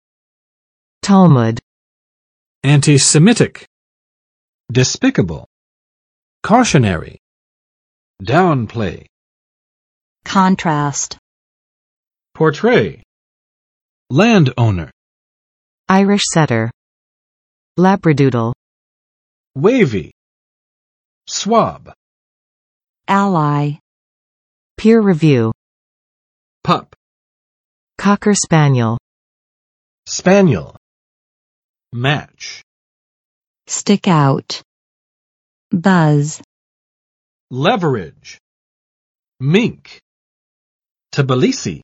[ˋtɑlmʌd] n.【宗】《塔木德经》（犹太教的法典）